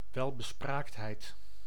Ääntäminen
IPA : /ˈɛləkwəns/